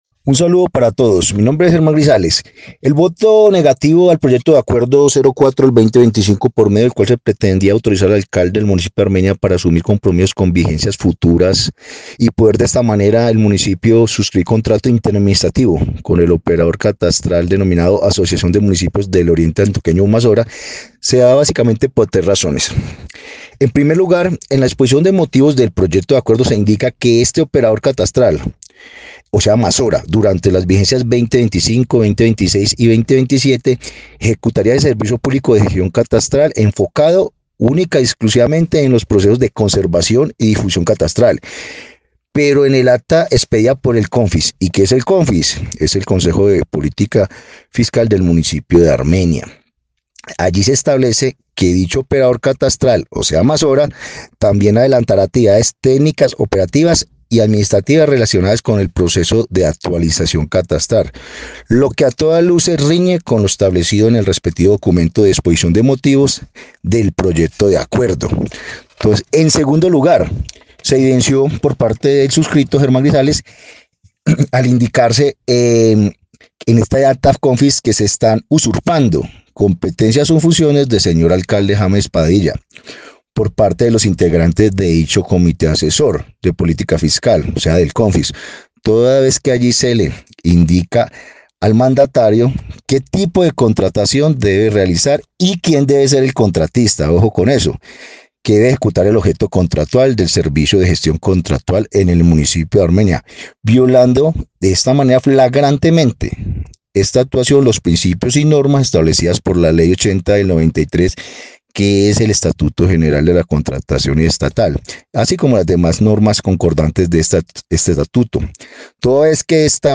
Germán Grisales, concejal de Armenia